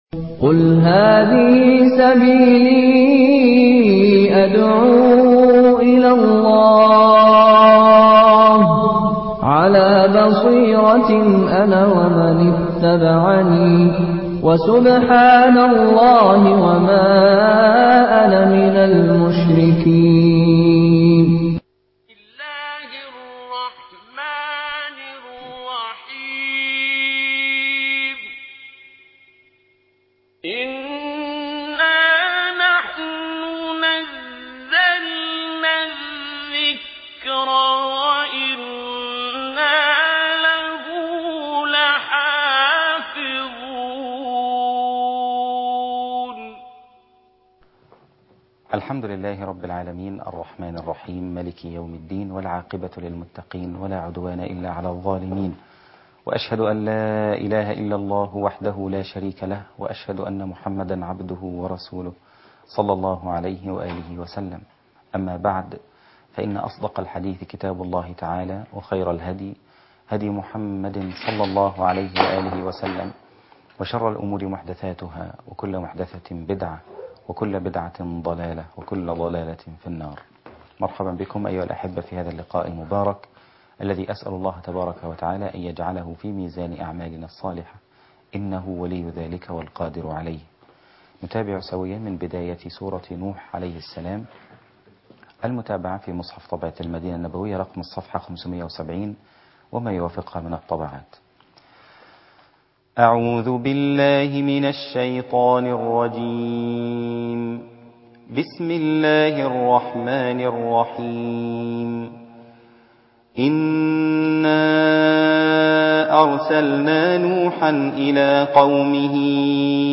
المقرأة